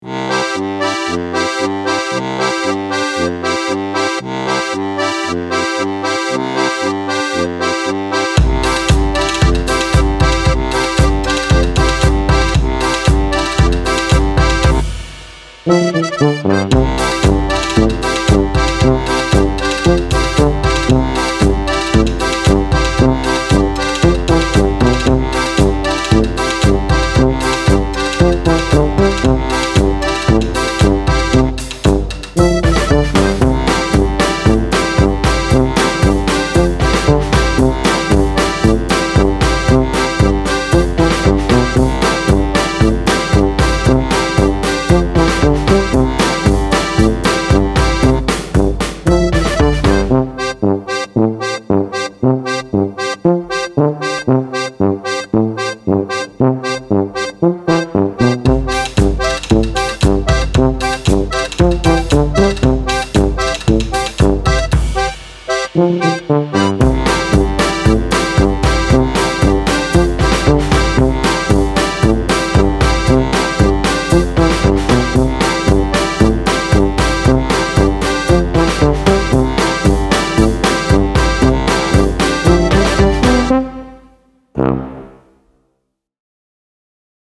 Style Style FolkWorld
Mood Mood BouncyBrightFunnyRelaxed
Featured Featured AccordionBassDrumsPercussion
BPM BPM 115